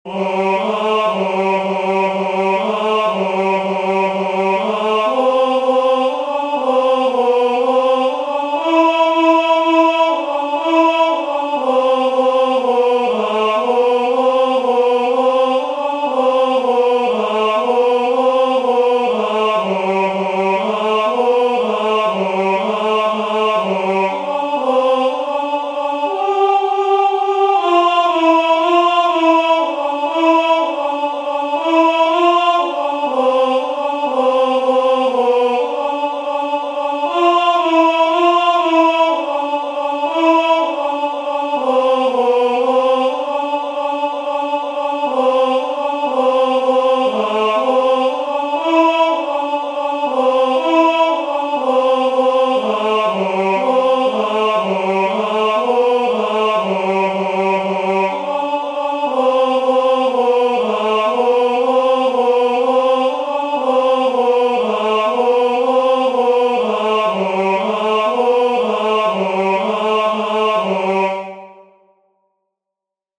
"Tollite jugum meum," the second responsory from the first nocturn of Matins, Common of Apostles